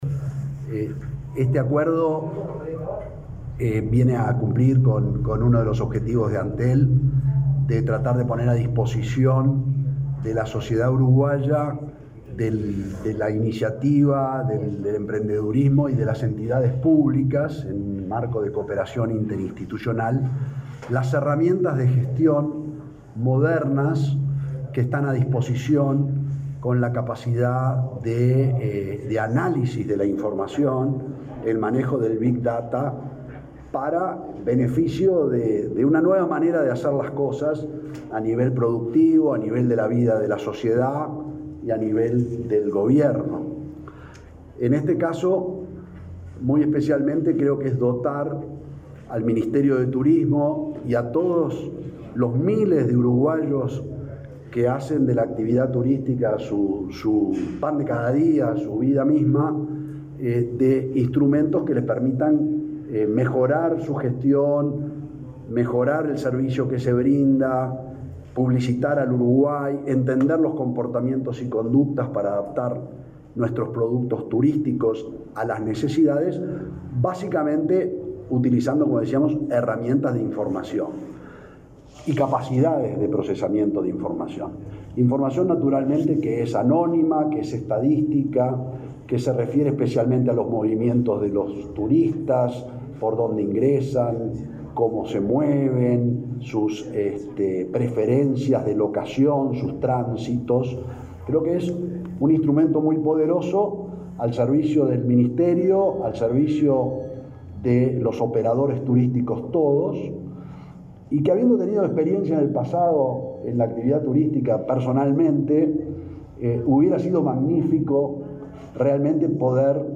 Declaraciones del presidente de Antel, Gabriel Gurméndez